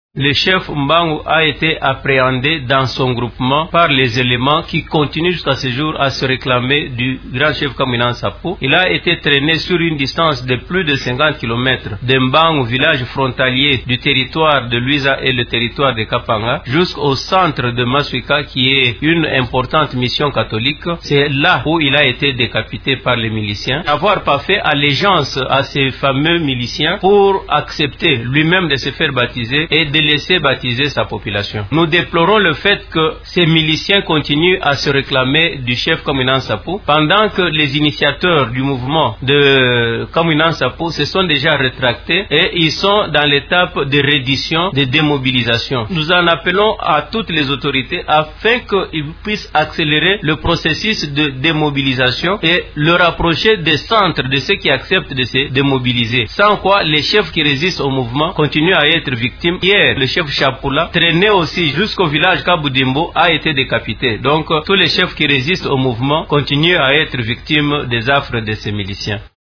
Vous pouvez écouter le témoignage du député provincial Manix Kabuanga en cliquant ici :